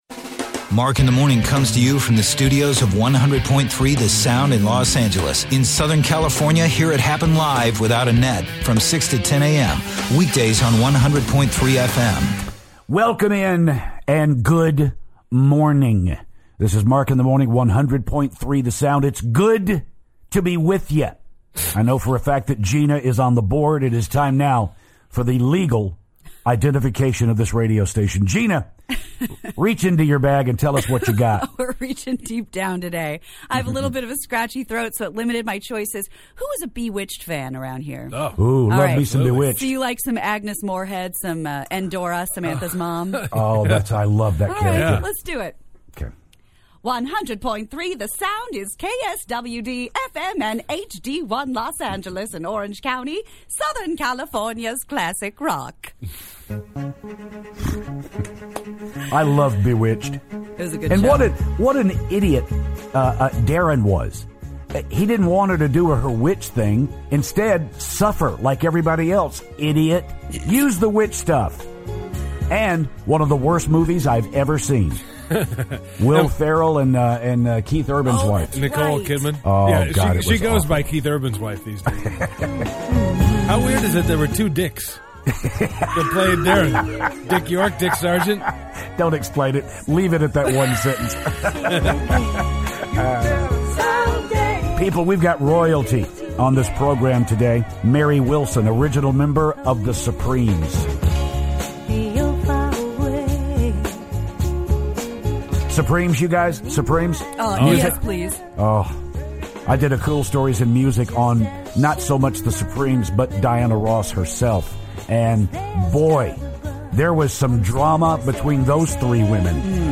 Founding member of The Supremes Mary Wilson calls the show